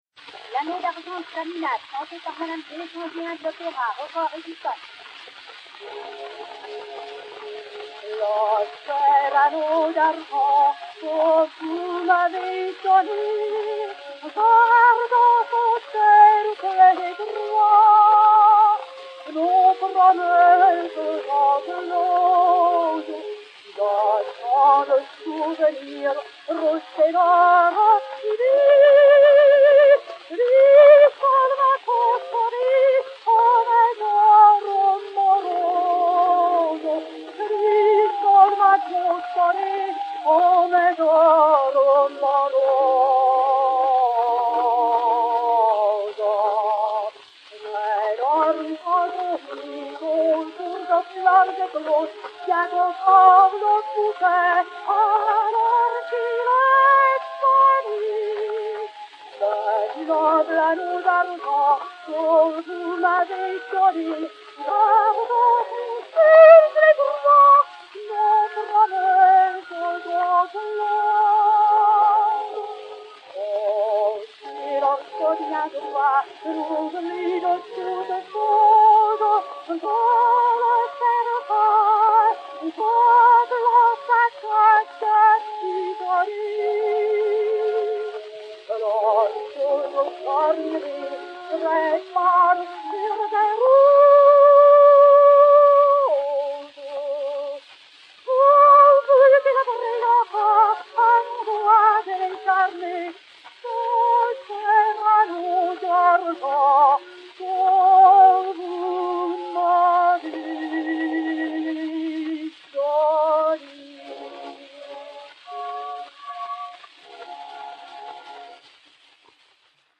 mélodie